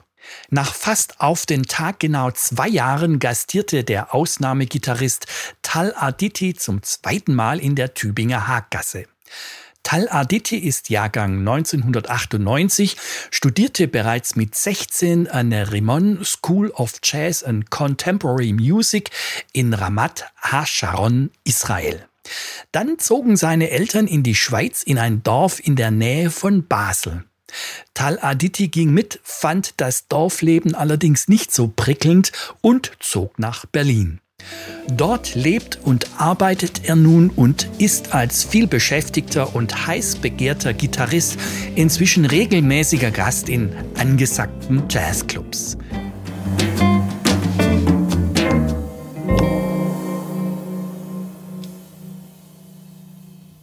live im Tübinger Jazzclub 2022